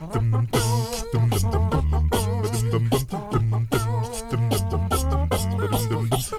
ACCAPELLA10D.wav